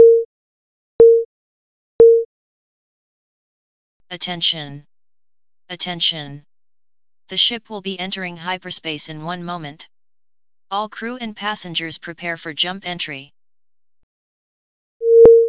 Attention tones sound.